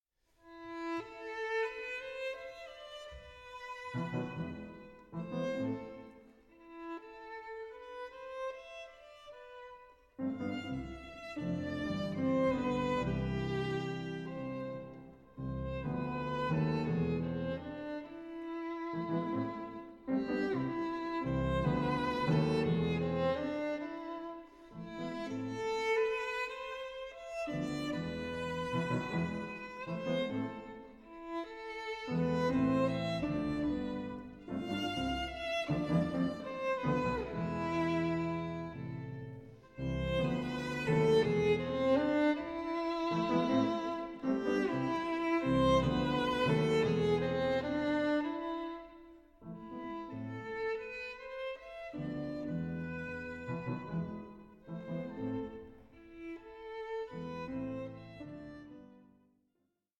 Nicht schnell, mit viel Ton zu spielen 4:16